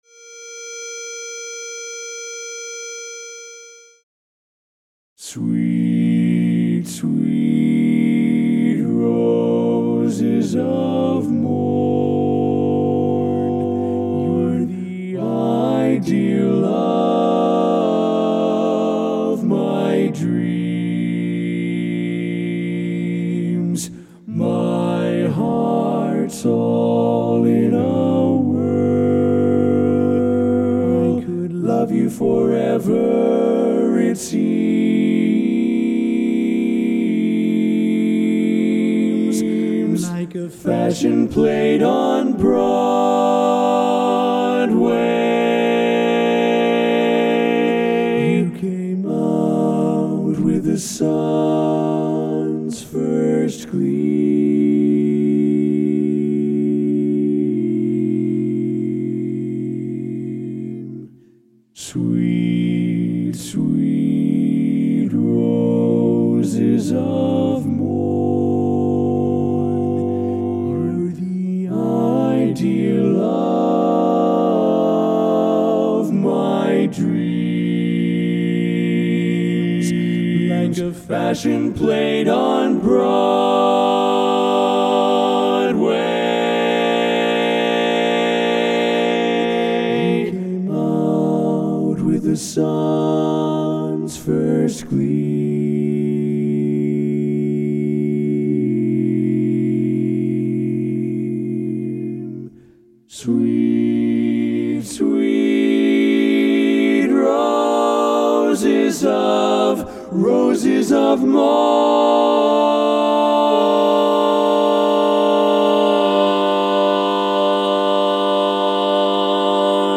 Barbershop